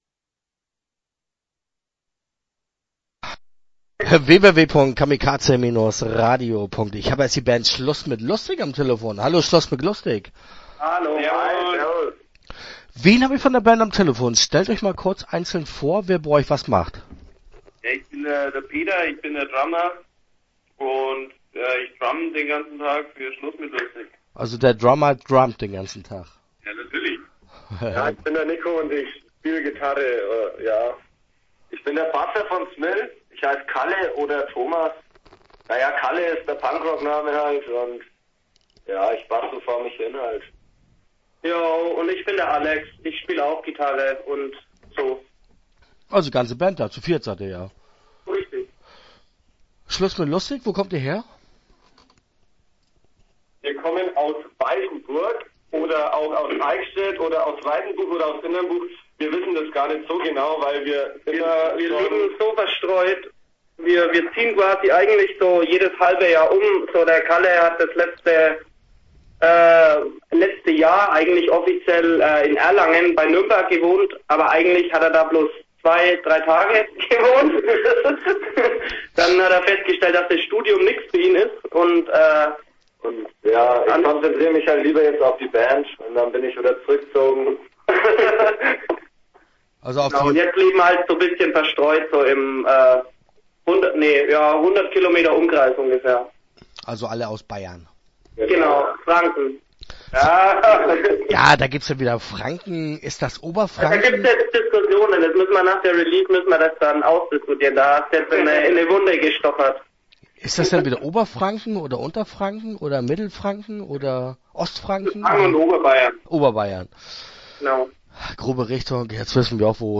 Schluss mit Lusitk - Interview Teil 1 (11:05)